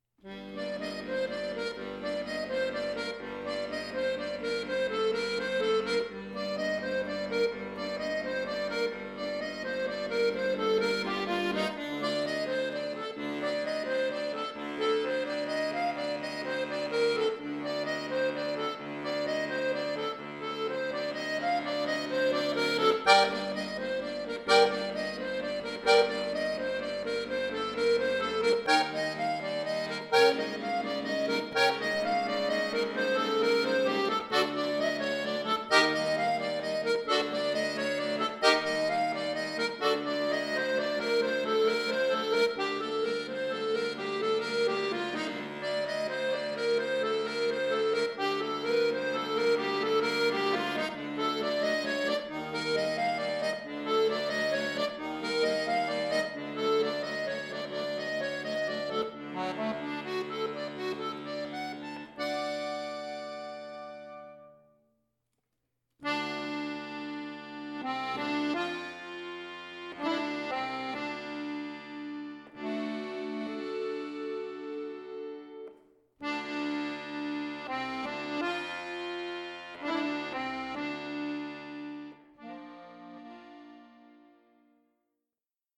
Berühmtes Gitarren-Bravourstück
in einem Arrangement für Akkordeon solo